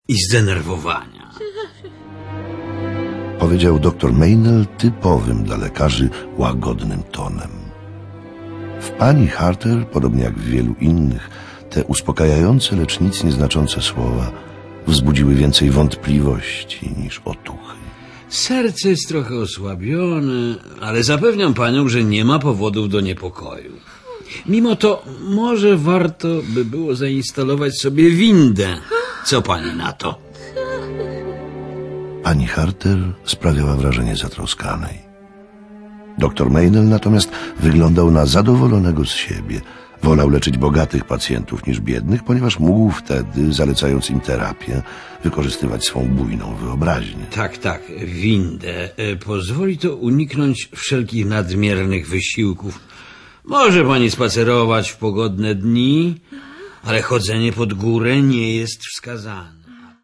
Sluchowisko przygotowane na podstawie opowiadania o tym samym tytule.